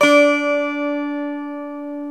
Index of /90_sSampleCDs/Club-50 - Foundations Roland/GTR_xAc 12 Str/GTR_xAc 12 Str 1
GTR X12 ST09.wav